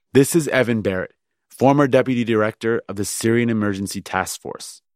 As you will see in the next two examples, it can also be found when /k/ is preceded or followed by other consonants.